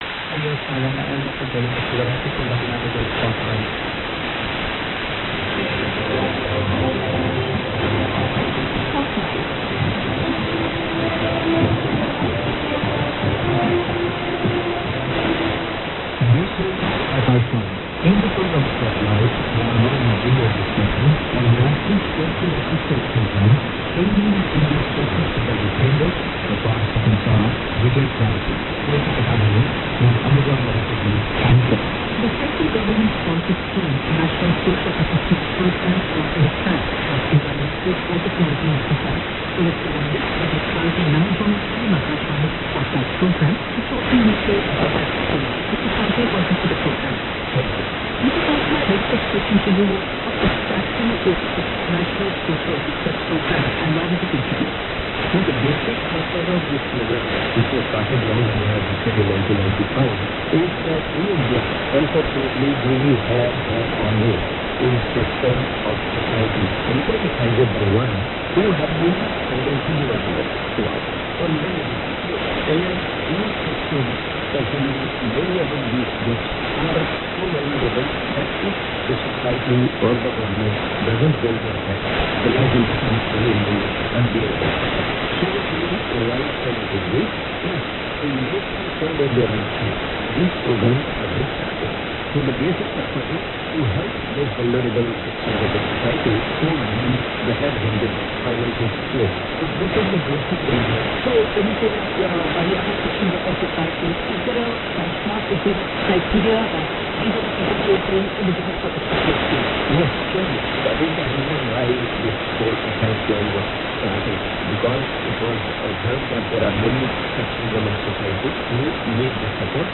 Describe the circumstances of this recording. I heard the 2000 UT airing during scheduled French with relatively good reception. Talk was about development projects promoted by Prime Minister Modi